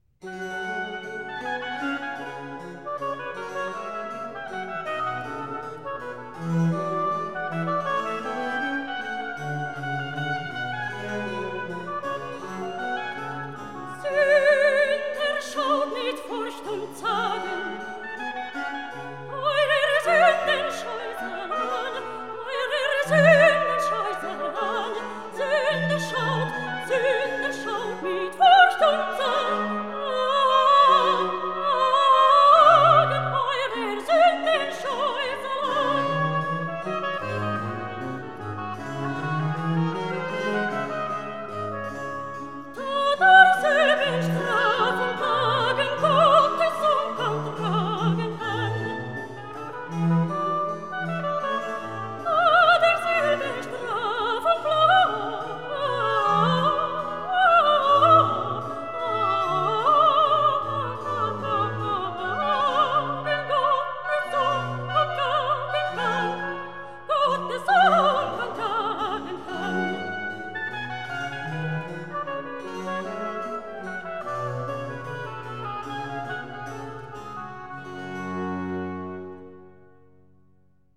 Arioso